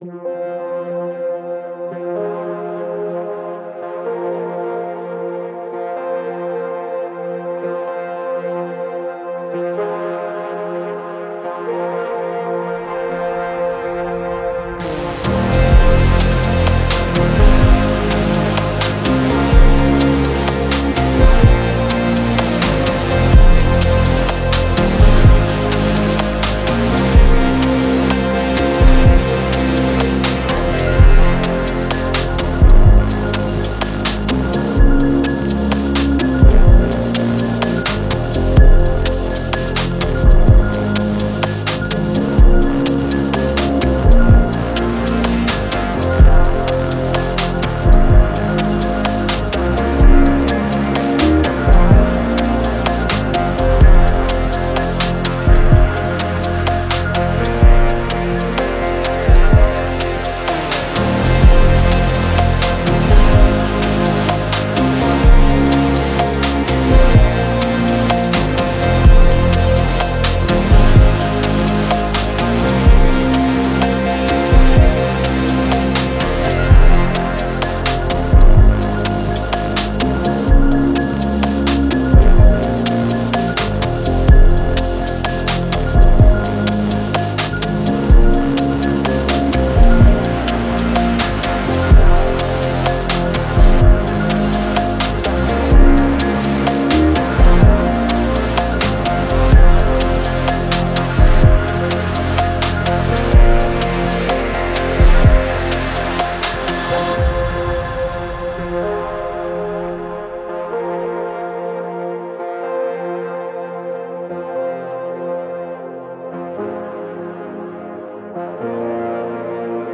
As the drama unfolds so the beat thickens.